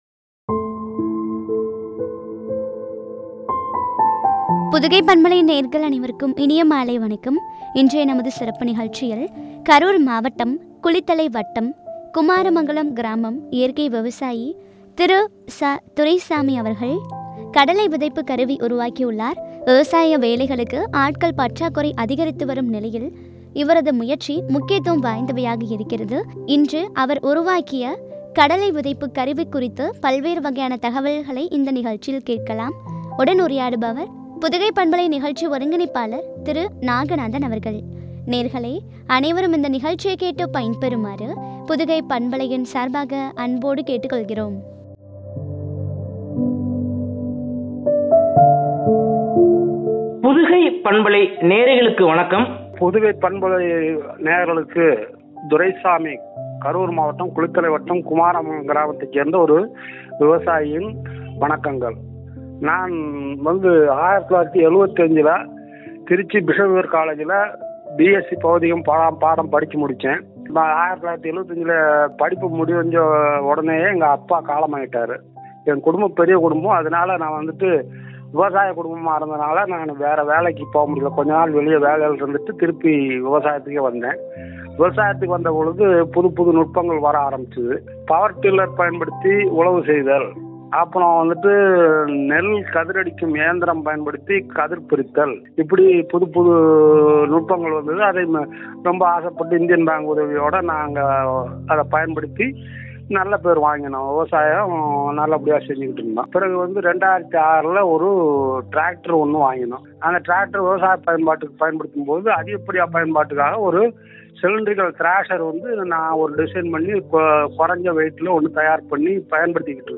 “சாதனை நாயகர்” குறித்து வழங்கிய உரையாடல்.